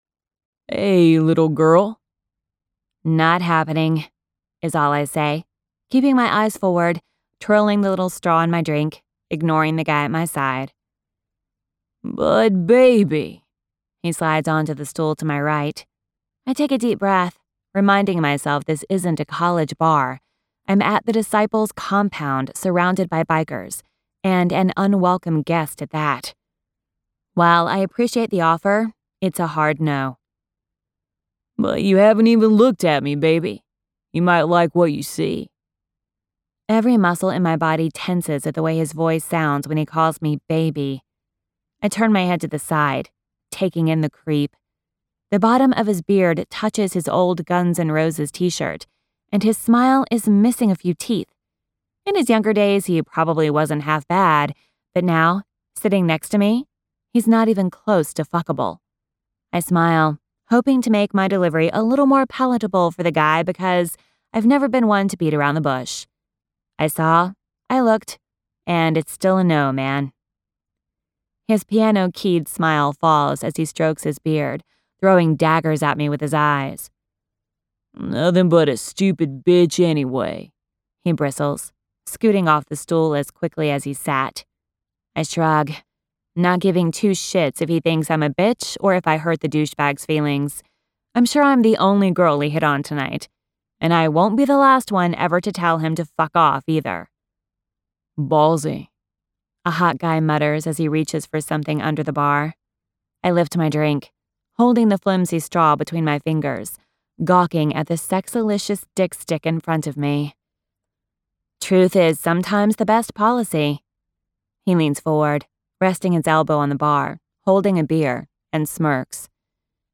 • Audiobook